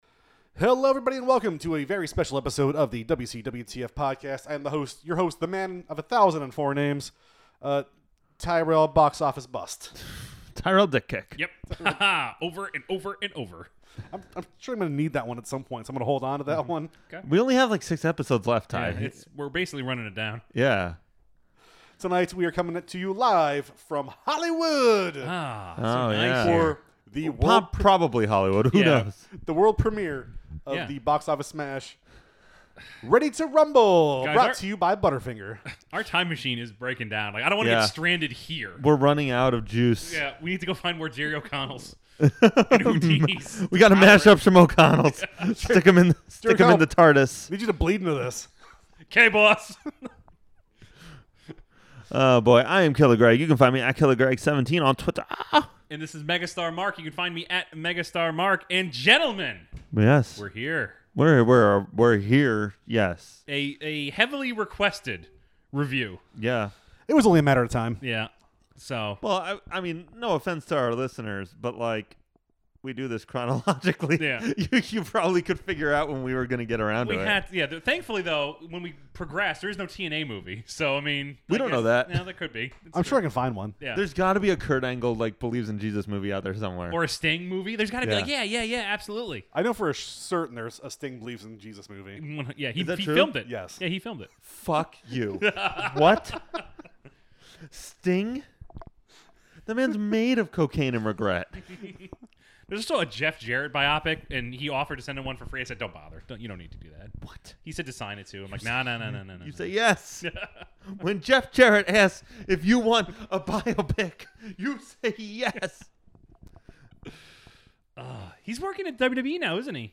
We’re back in studio with something of a review of the WCW film Ready to Rumble!
As a bonus you’ll hear us talking about Star Wars, Avengers, and so much more! So buckle up, strap in, and get ready for one of the most relaxed episodes we’ve done in a long time.